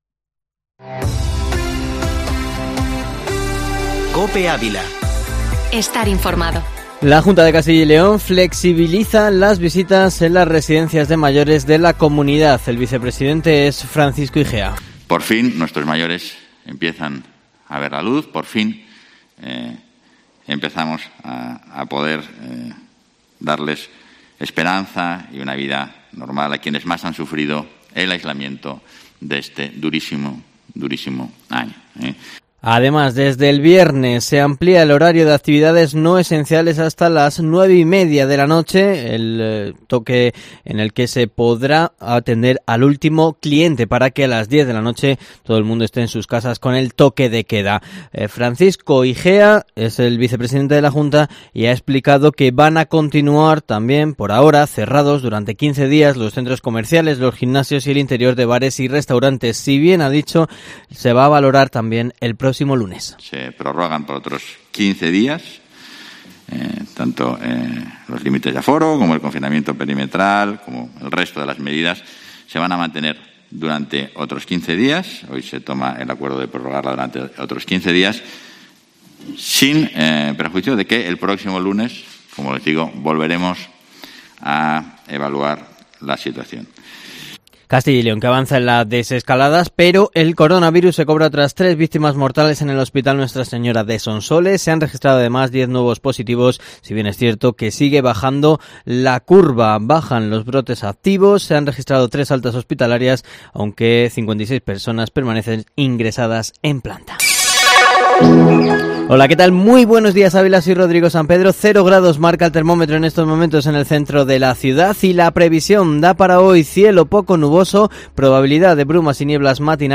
Informativo matinal Herrera en COPE Ávila 23/02/2021